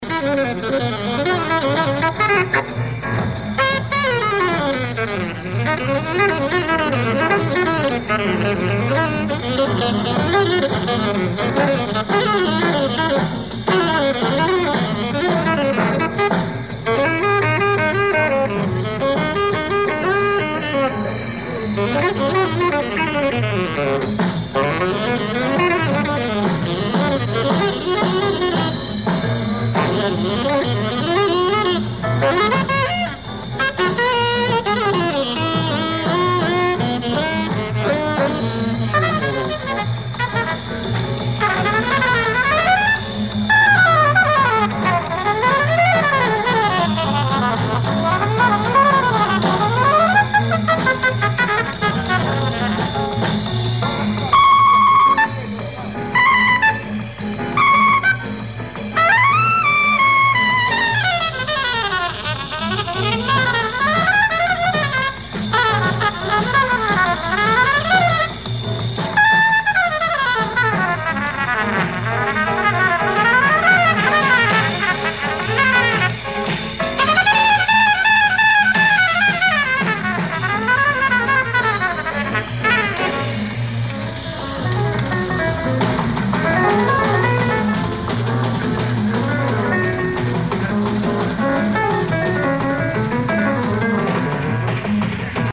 Tracks 1&2:Live At Basin Street Club, NYC April 28th, 1956
Tracks 3-5:Live At Basin Street Club, NYC May 6th, 1956